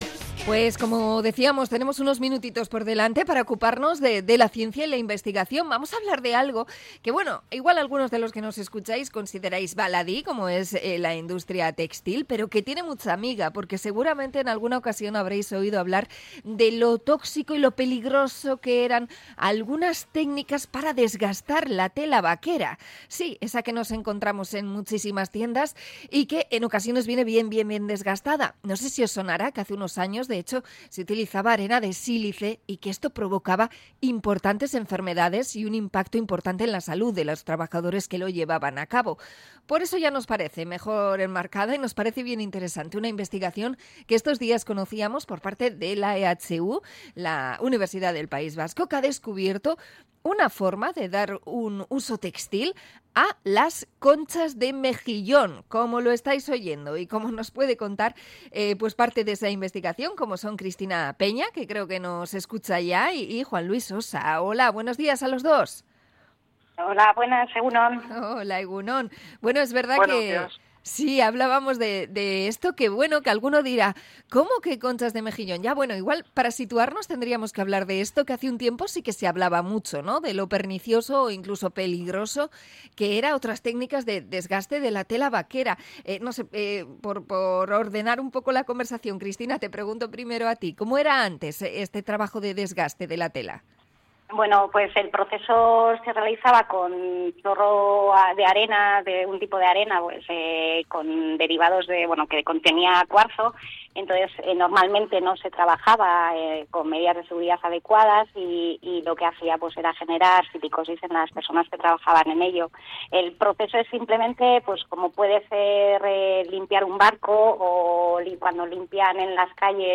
Entrevista a investigadores de la EHU sobre desgaste del textil vaquero